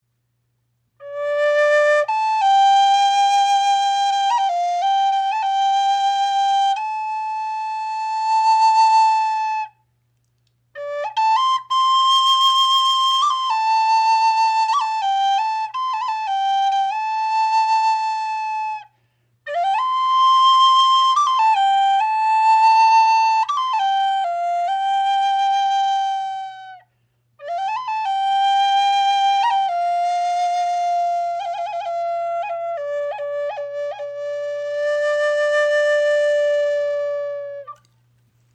Kestrel Flöte in D-Moll | Aromatische Zeder | hell, lebendig, vielseitig
Dank ihrer hellen Tonhöhe eignet sich die Kestrel perfekt für Trommelkreise, denn sie hebt sich mühelos von tiefer klingenden Instrumenten ab und bringt Leichtigkeit in das gemeinsame Spiel.
So entsteht ein warmer, voller und resonanter Klang, der tief berührt.